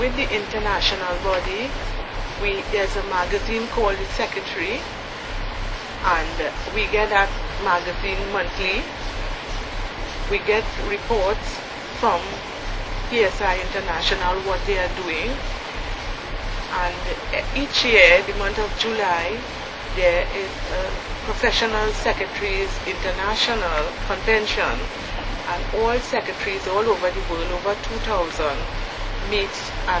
dc.description1 audio cassetteen
dc.typeRecording, oralen